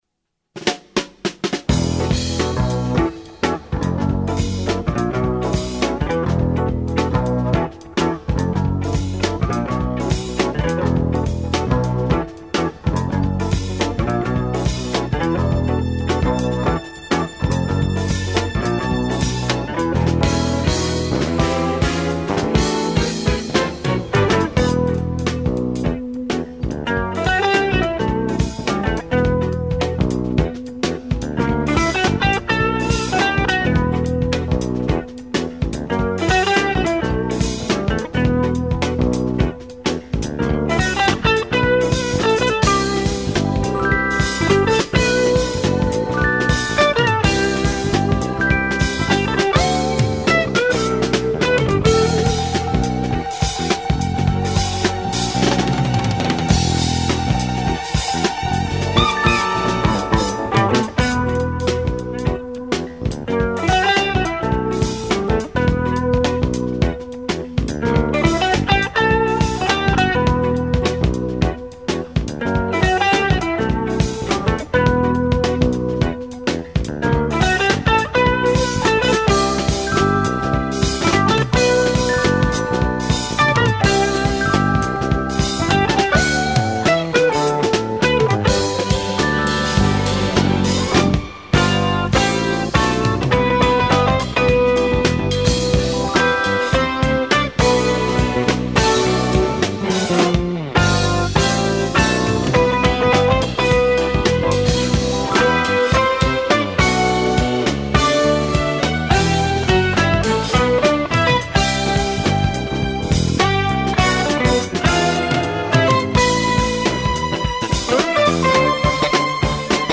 너무너무 감사합니다~ 맬로디에 스트링이 입혀질줄이야..허...